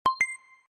medal-sound.wav